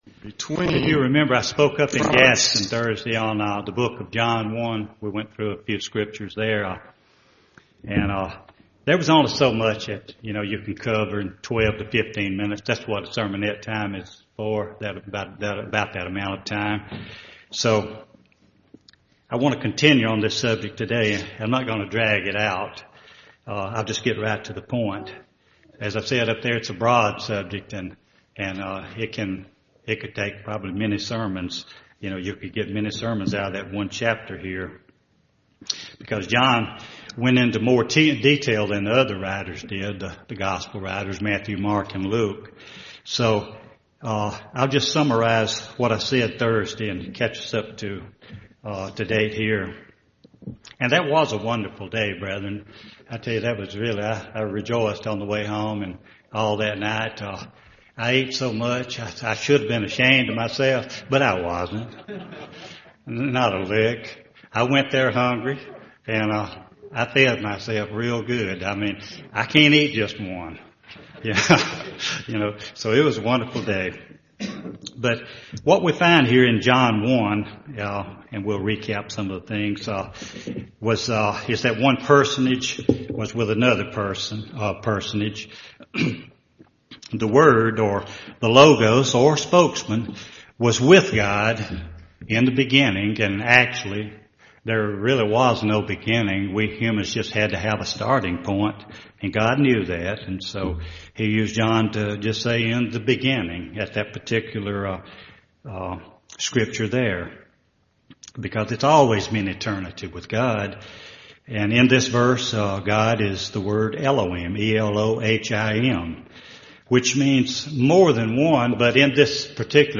Given in Birmingham, AL
UCG Sermon Studying the bible?